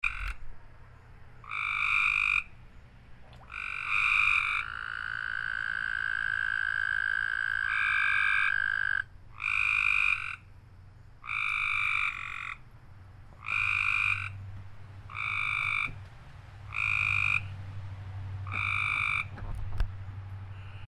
Despite the lack of rain, the tree frogs have been calling every night from our small pond.
The croak calls are the tree frogs; the sweeter trill is an American toad.
copesgraytreefrogcalls.mp3